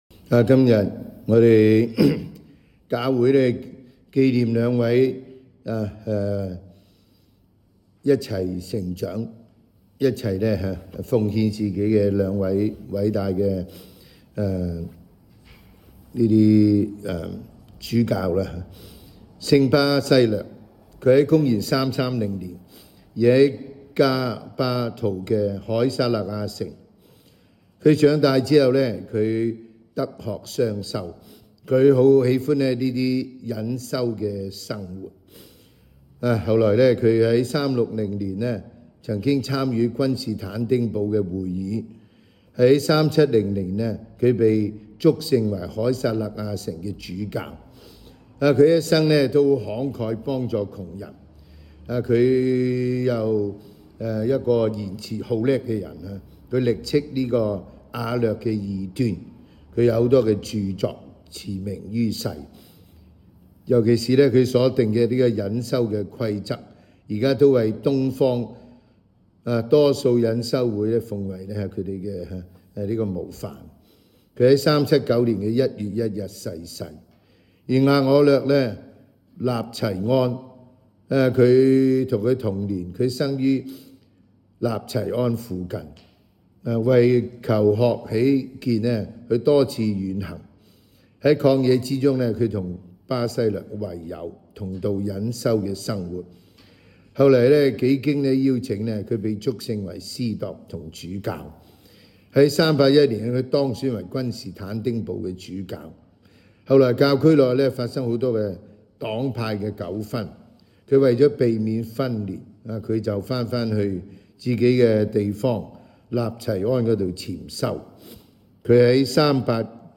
每日講道及靈修講座